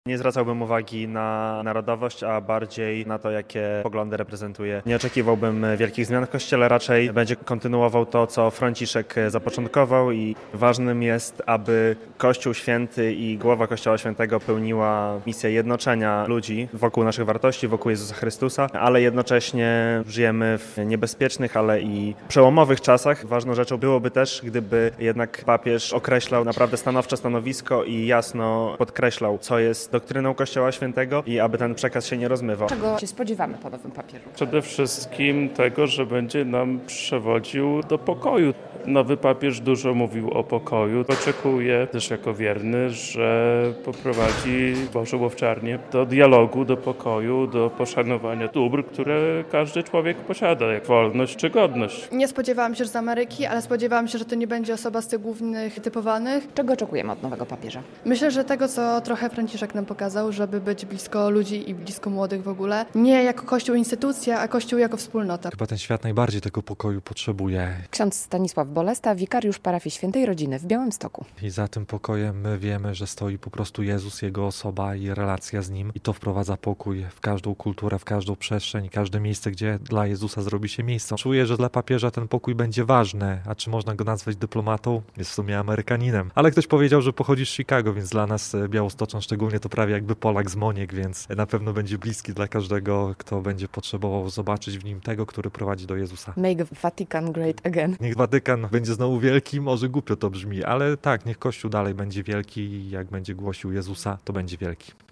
Czego oczekują wierni od nowego papieża? - relacja